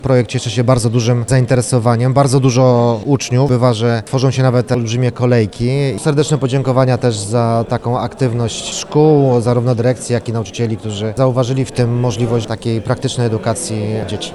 O popularności programu mówi wicemarszałek województwa mazowieckiego Rafał Rajkowski: